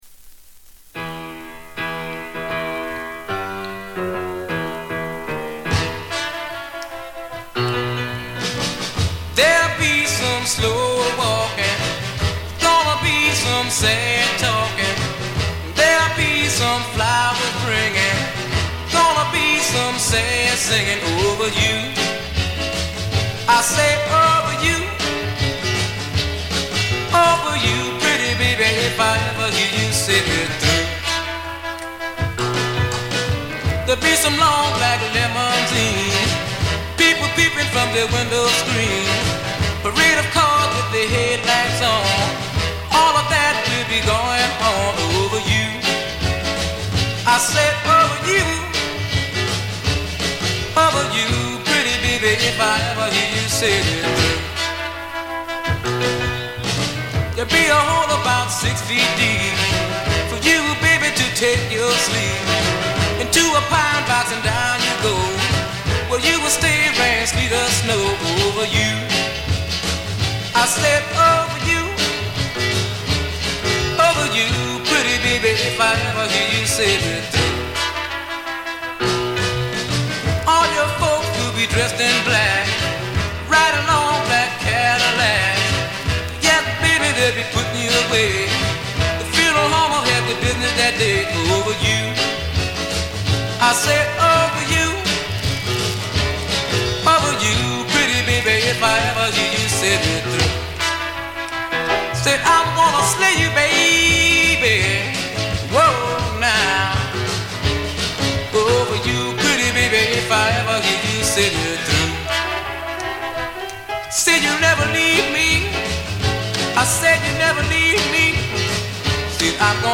静音部で微細なバックグラウンドノイズが聴かれる程度。
モノラル盤。
試聴曲は現品からの取り込み音源です。